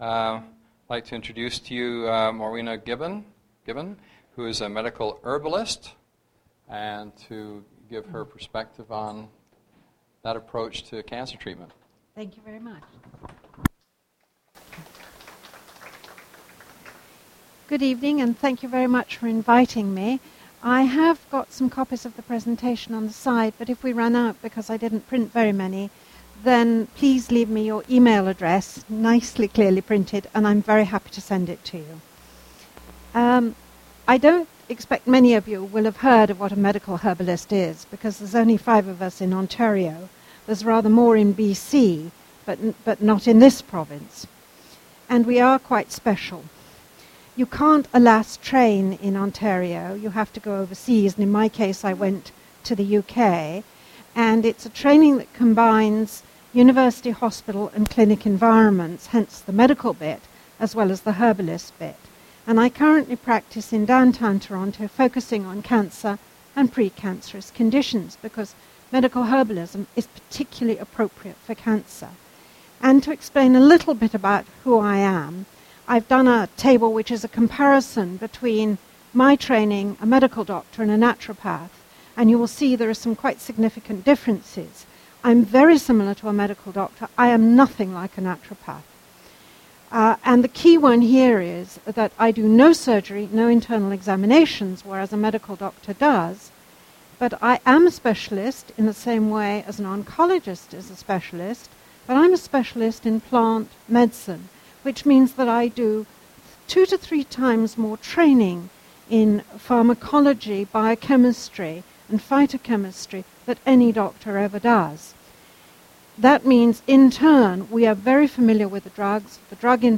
PCCN Brampton General Meeting